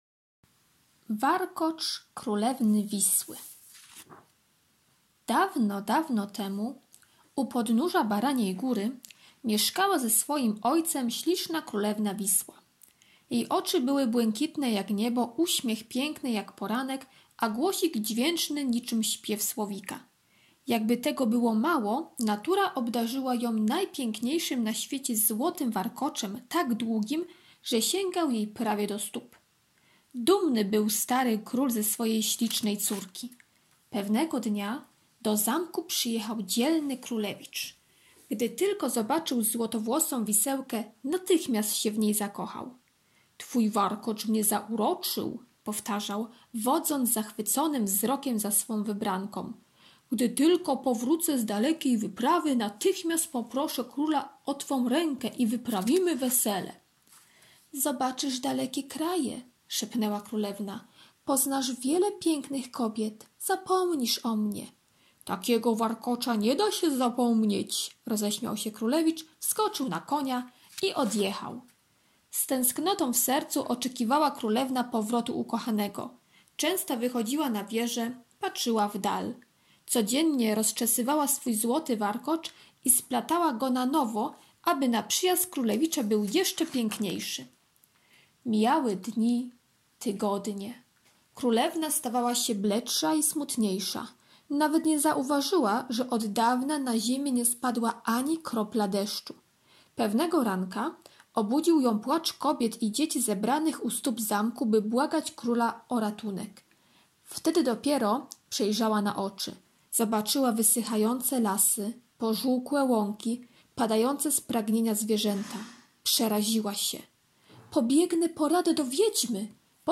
Jest też możliwość posłuchania legendy czytanej przez nauczyciela (plik mp3 w załączniku).